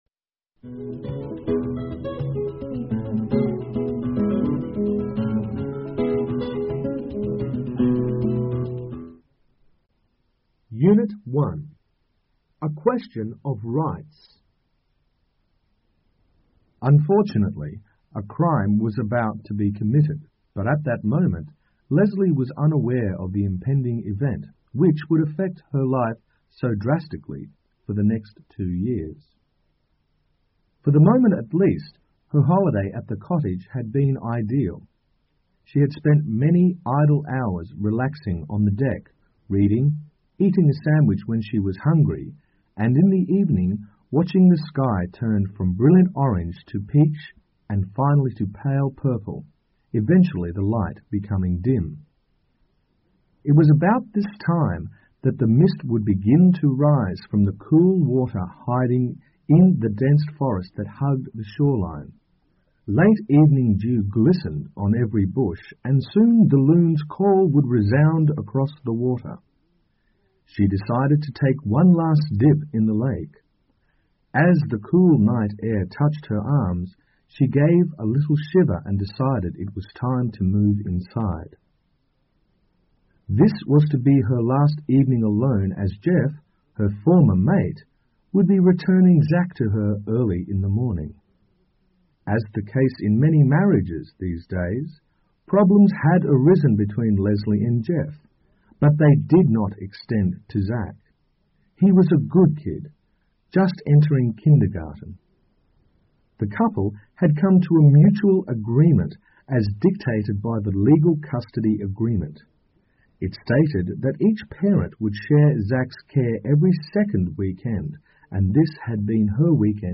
2011年12月英语四级听力精练第1套：短对话 听力文件下载—在线英语听力室